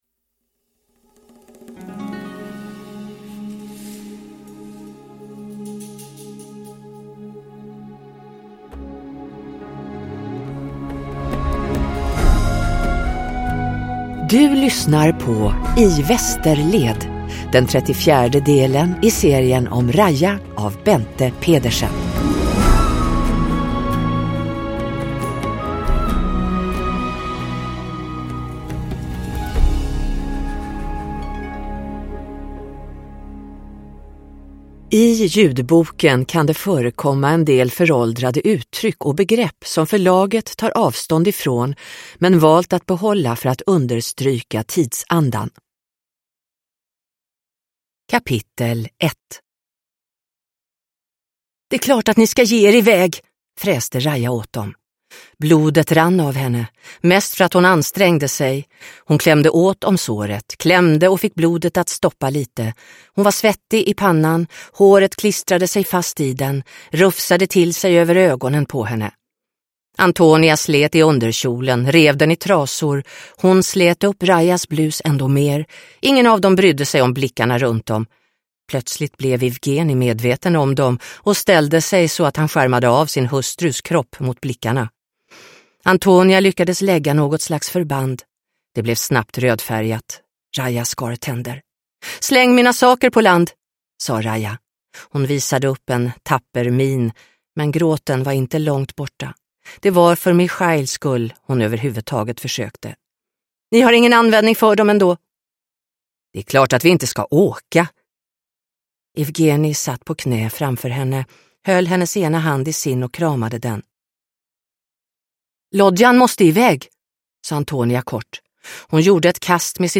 I västerled – Ljudbok – Laddas ner